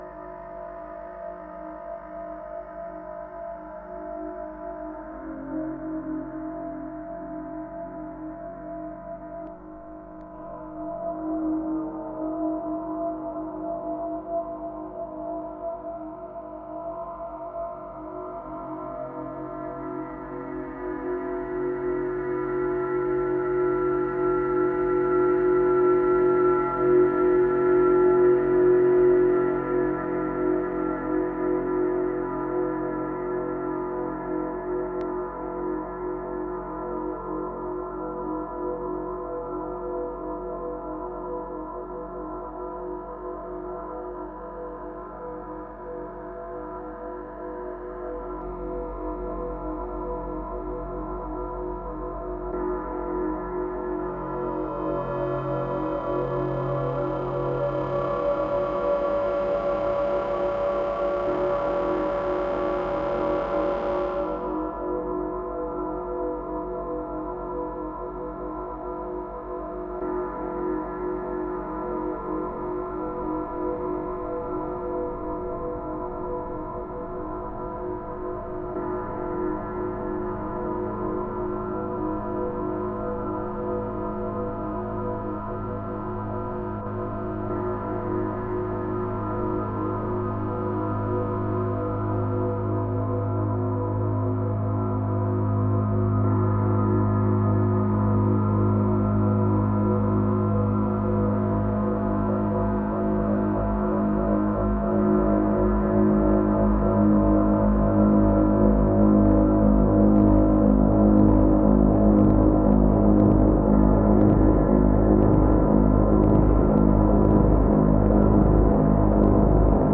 ограничился саундскейпом "на пути к порталу"
итак, дд6-океан-бигскай-лупер в 1 слой (начало - конец, звук типа колокола - стык лупа)) на входе сигнала нет, полный самовозбуд треск - это океан подпердывает
ухи берегите, там жестоко